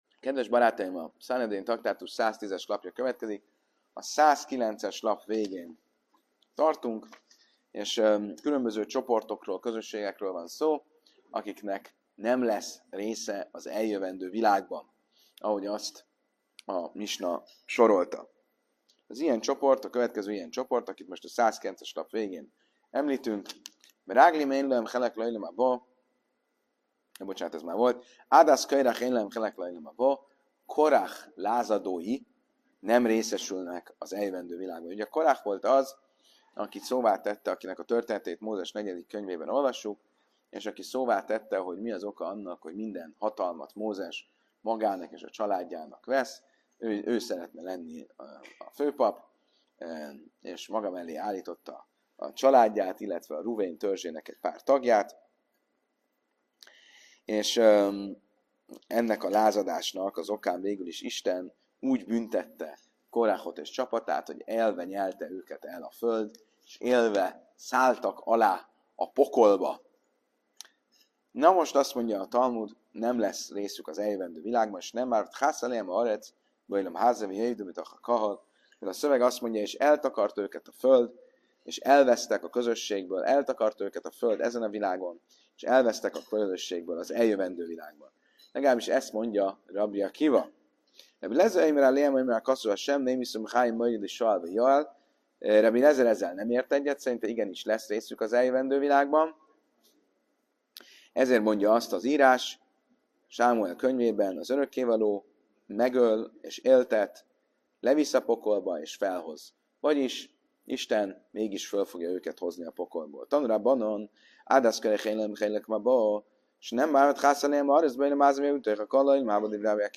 Az előadás központi dilemmája, hogy ezek a csoportok – bármilyen súlyos bűnt követtek is el – vajon valóban örökre elvesztek-e, vagy Isten könyörülete révén mégis lesz részük a túlvilági jutalomban, illetve a jövőbeni megváltásban.